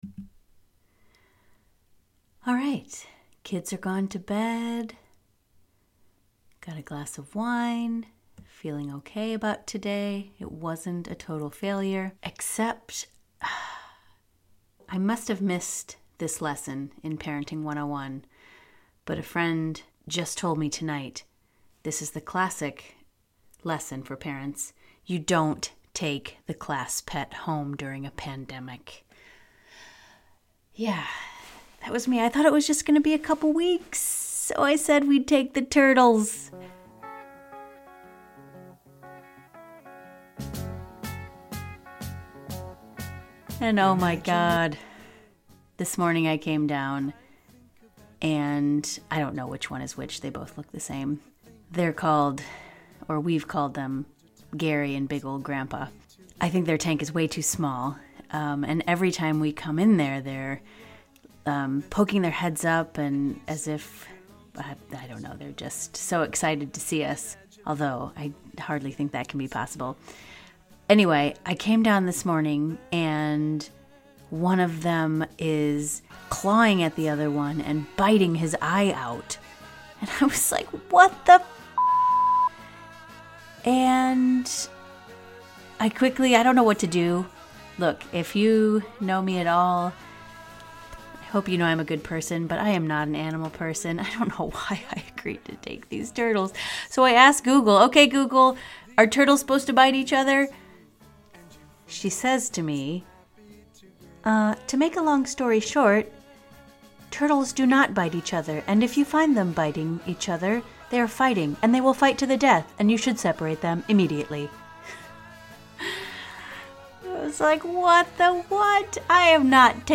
Recorded on my bathroom floor, in under 4 minutes, if I can help it.